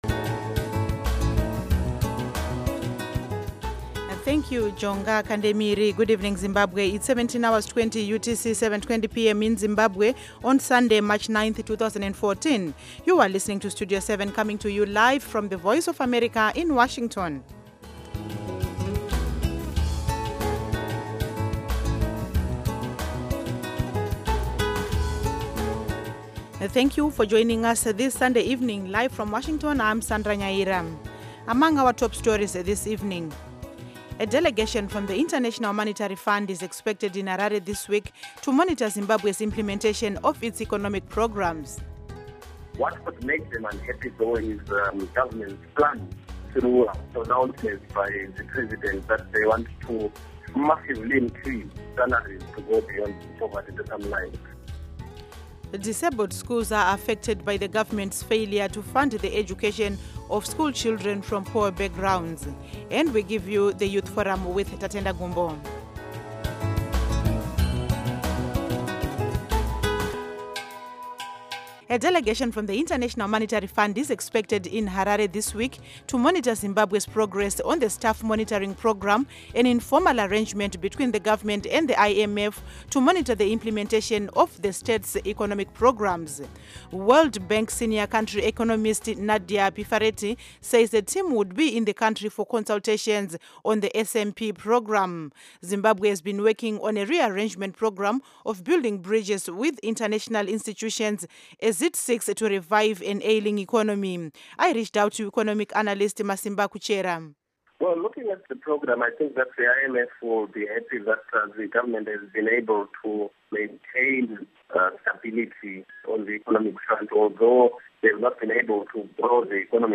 Studio 7 for Zimbabwe provides comprehensive and reliable radio news seven days a week on AM, shortwave and satellite Schedule: Monday-Friday, 7:00-9:00 p.m., Saturday-Sunday, 7:00-8:00 p.m., on Intelsat 10 repeats M-F 9-11 p.m. Local Time: 7-9 p.m. UTC Time: 1700-1900 Duration: Weekdays: 2 hours; Weekends: 1 hour Listen: MP3